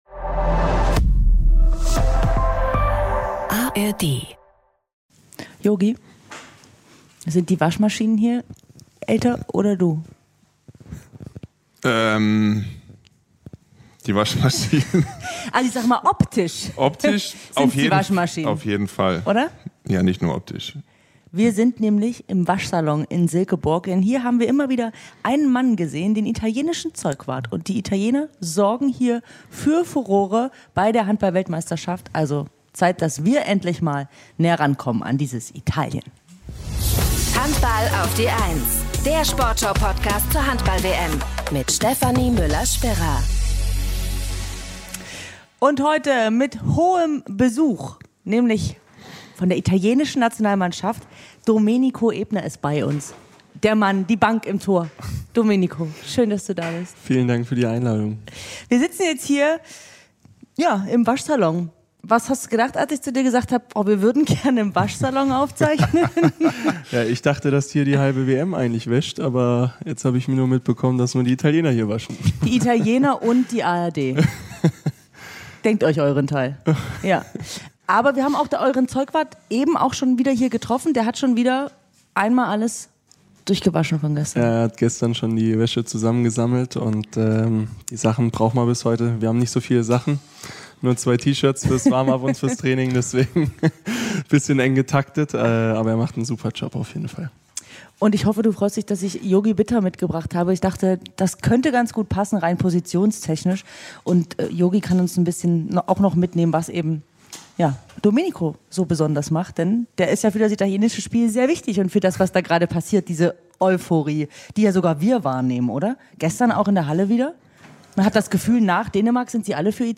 Verabredet haben sich die drei dort, wo die Italiener bei der WM ihre schmutzige Wäsche waschen: Im Waschsalon in Silkeborg.